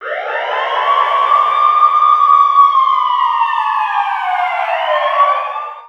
BIG WAIL1 -L.wav